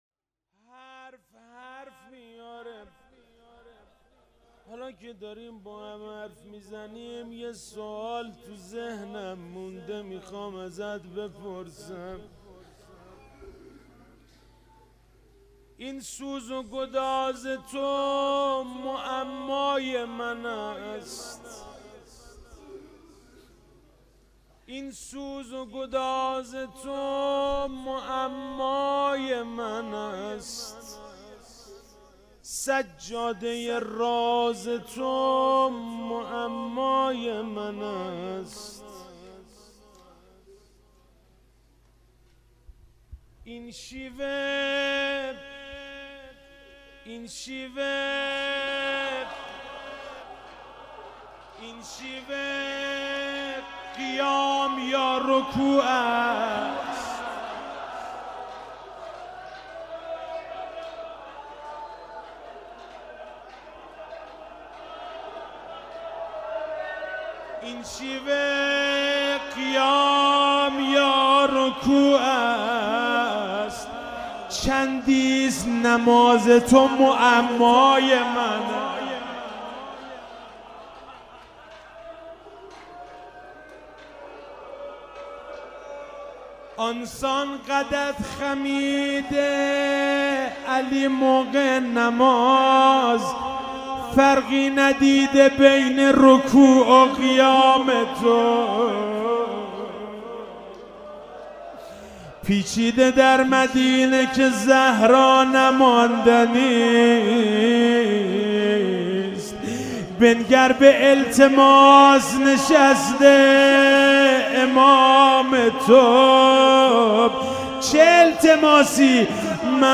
روضه - این سوز و گداز تو معمای من است